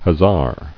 [hus·sar]